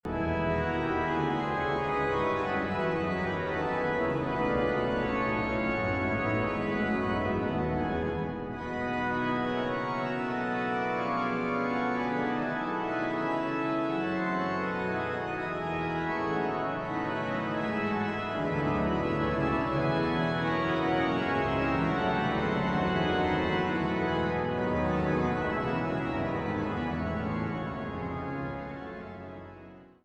Die Orgeln im St. Petri Dom zu Bremen
Orgel